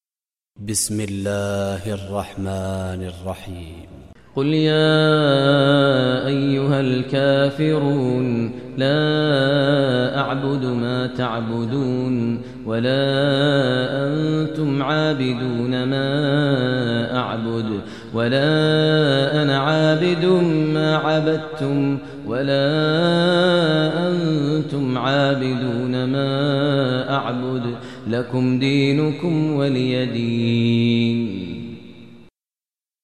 Surah Kafirun Recitation by Maher Mueaqly
Surah Kafirun, listen online mp3 tilawat / recitation in Arabic recited by Imam e Kaaba Sheikh Maher al Mueaqly.